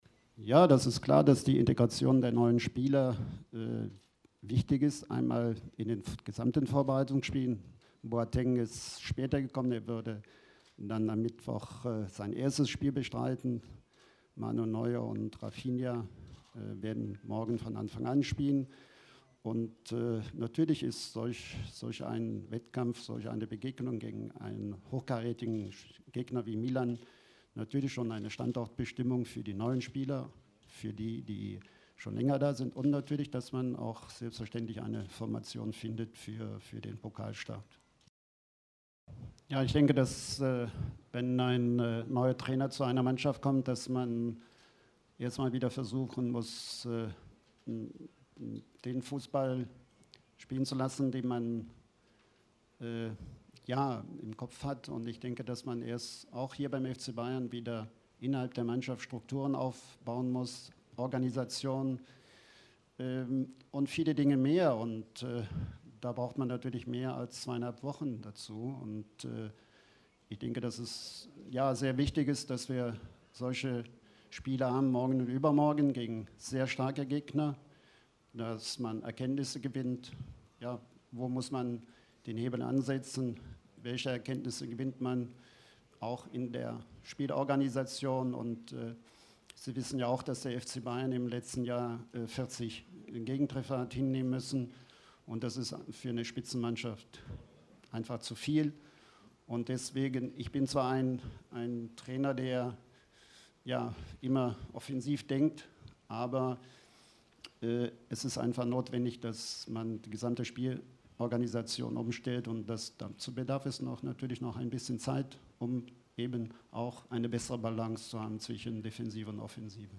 Der Trainer des FC Bayern München, Jupp Heynckes, hat am Vorabend der Audi Cups die Befürchtungen vor einer längeren Verletzung von Franck Ribéry als nicht so schlimm eingestuft. „Ich sehe die Verletzung nicht so dramatisch“, sagte der neue Coach auf der Pressekonferenz vor dem Audi Cup. Weitere Themen der Pressekonferenz (die ebenfalls in dem O-Ton-Paket enthalten sind): Bedeutung des Audi Cups, Ziele für die Saison, Pokalspiel gegen Braunschweig, die Rolle des Trainers)